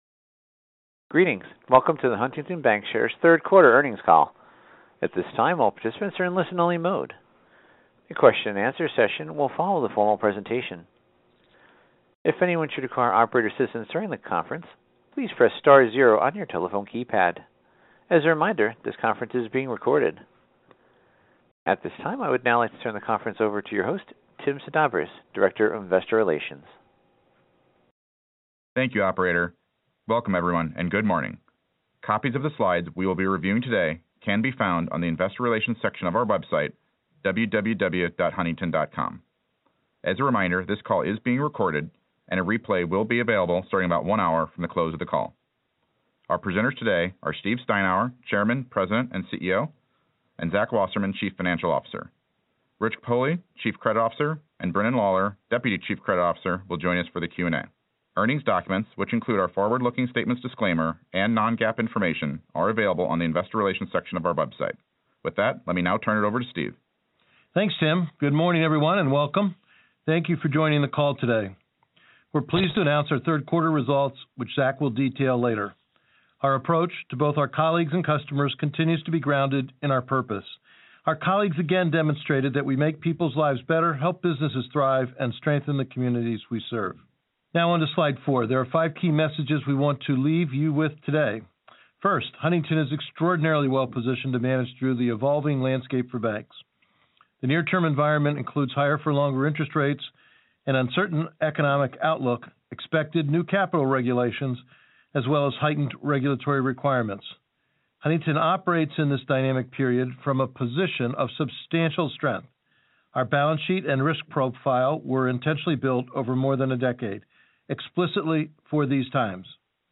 Earnings Webcast Q3 2023 Audio